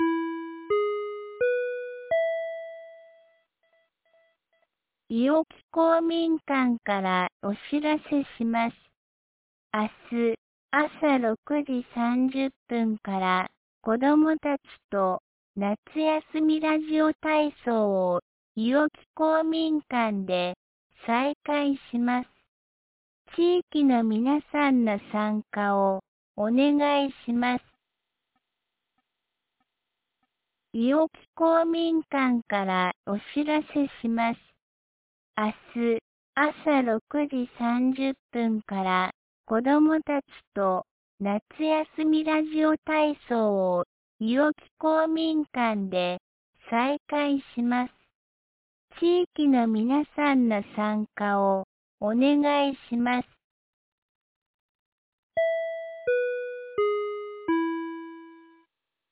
安芸市放送内容 伊尾木ラジオ体操
2025年08月24日 17時11分に、安芸市より伊尾木へ放送がありました。